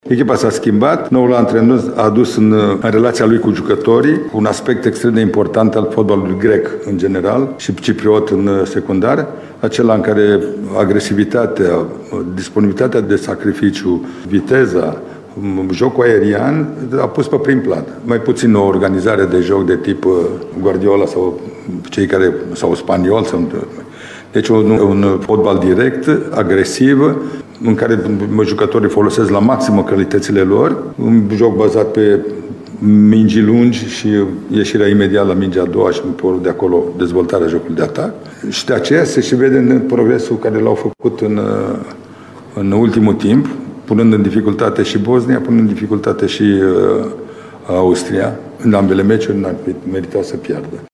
Și selecționerul Mircea Lucescu vede o schimbare în bine a Ciprului, care ne-a fost adversară și anul trecut, în Liga Națiunilor: